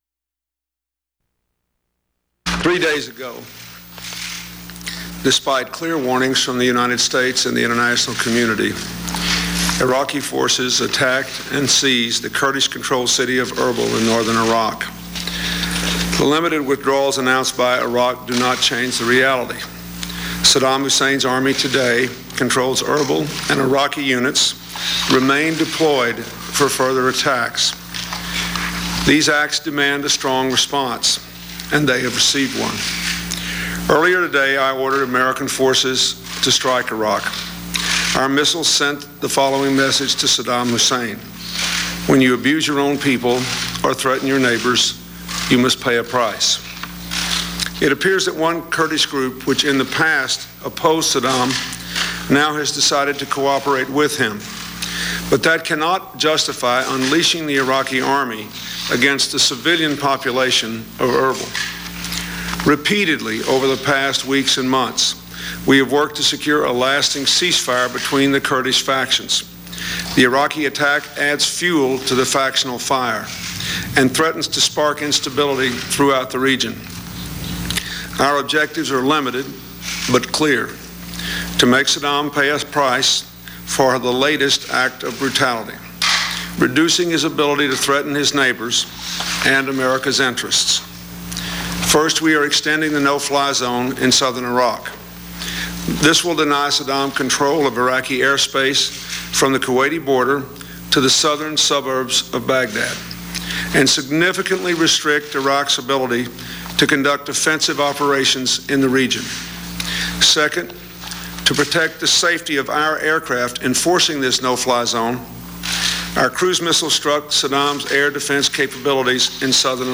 U.S. President Bill Clinton announces the bombing of Iraqi military emplacements following Saddam Husseins attacks on his own Kurdish nationals